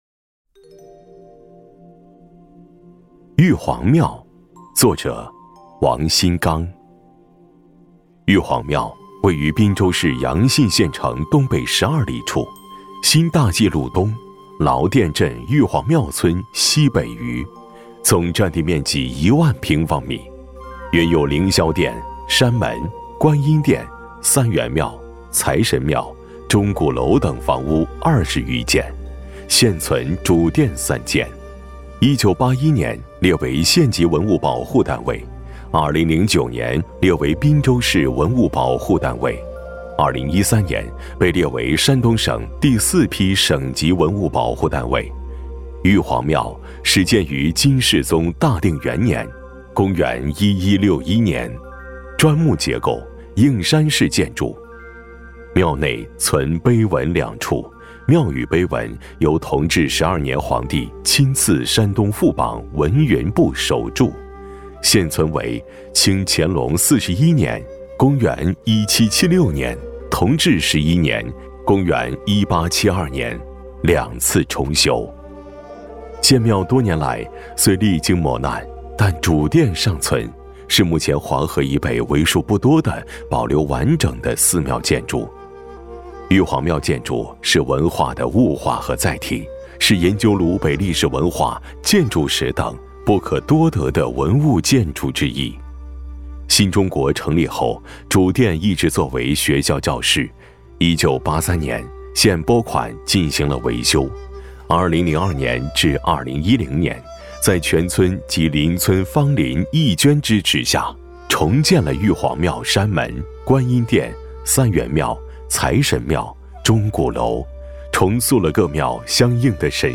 有声馆刊 | 玉皇庙